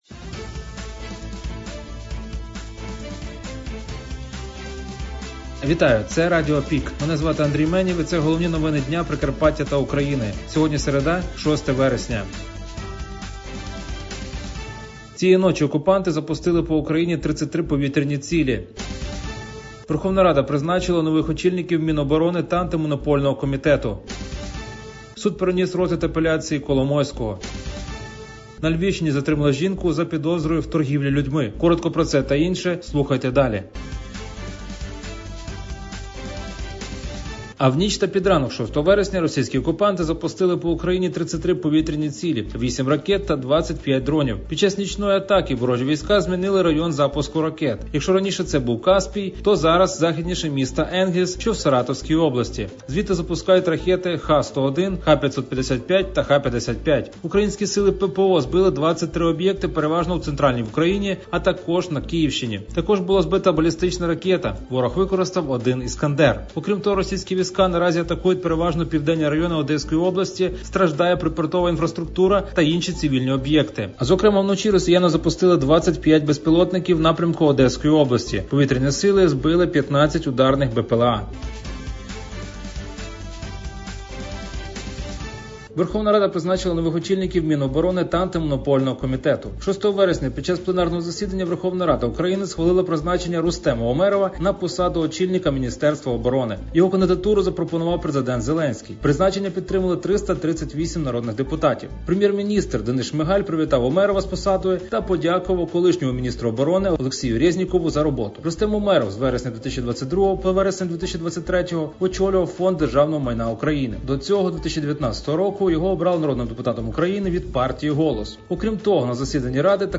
Пропонуємо вам актуальне за день – у радіоформаті.